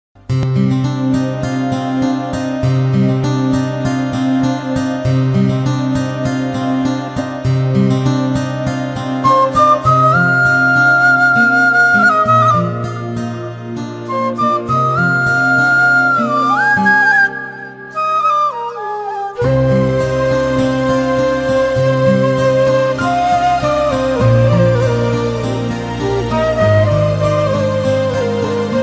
RELATED GUITAR RINGTONES